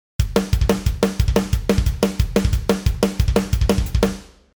スラッシュメタルをはじめ、デス・ハードコアパンク等でも多用される、スタスタスタスタのリズムです。
8ビートの基本パターンやDビートを、倍の速さで叩きます。
（聞き取りやすいように、ちょっと遅めにしてあります。実際はもっと早く演奏されることが多いです）
スラッシュビート
• スラッシュビート＝頭を振りたくなるスピード感と突撃感
thrashbeat.mp3